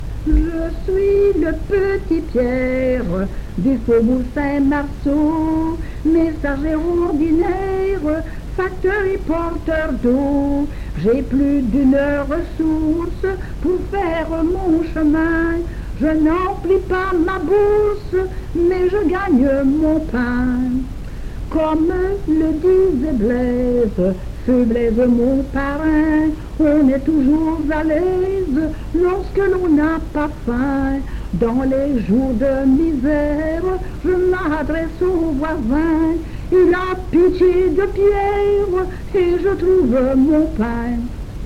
Genre : chant
Type : chanson narrative ou de divertissement
Lieu d'enregistrement : Jolimont
Support : bande magnétique
Vieille chanson.